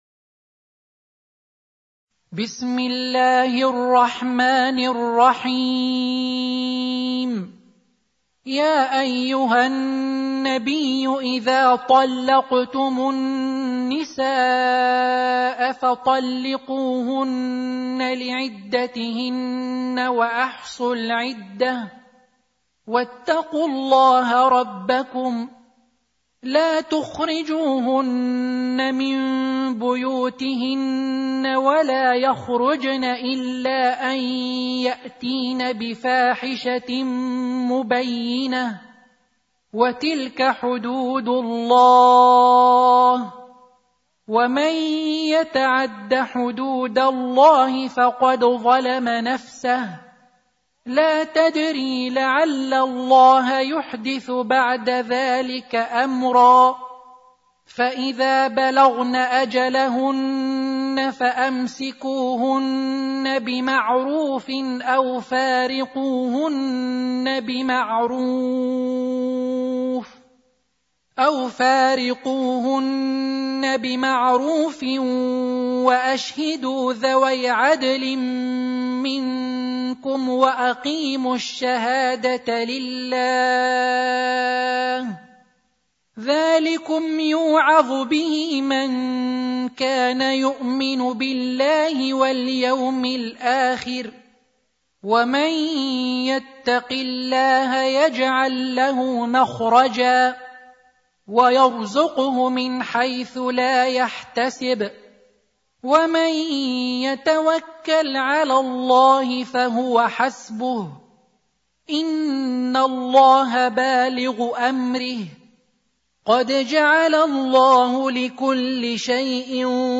65. Surah At-Tal�q سورة الطلاق Audio Quran Tarteel Recitation
Surah Sequence تتابع السورة Download Surah حمّل السورة Reciting Murattalah Audio for 65.